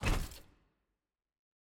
sfx-jfe-ui-roomselect-room-locked.ogg